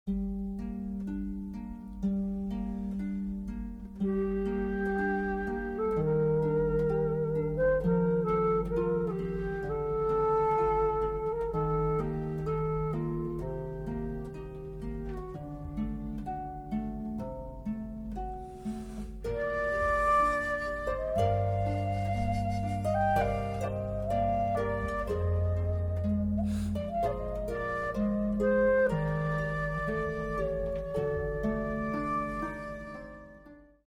06 harpe Floating.mp3